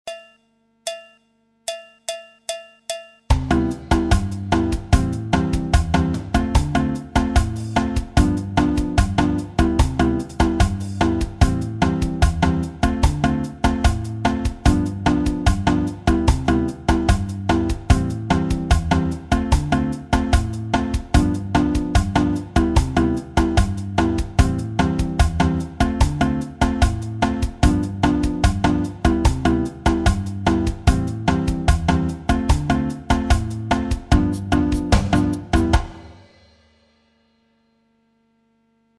La guitare brésilienne et les rythmes brésiliens
J'ai mis un pattern de batterie avec clave samba et tambourim 1 samba.
La samba en anatole
Nouvelle figure tirée de la figure samba 1 avec variation harmonique en anatole sur les accords suivants.